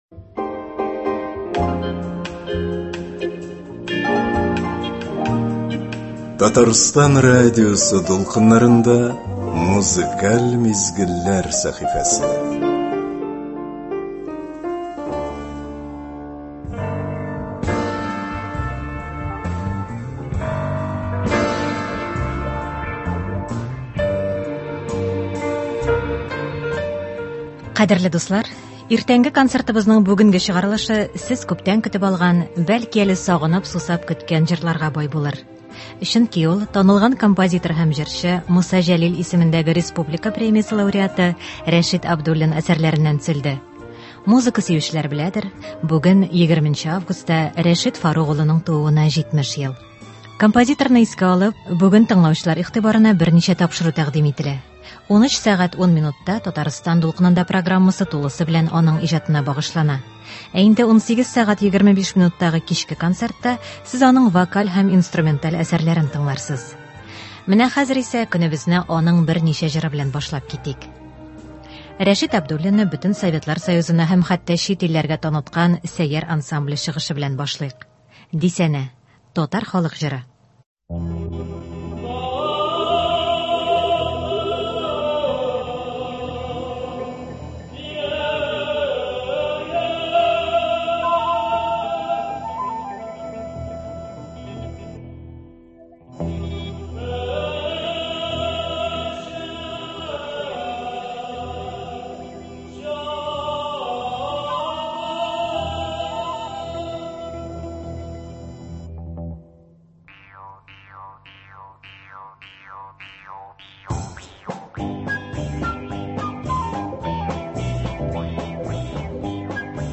Иртәбезне моңлы җырлар белән каршылыйк!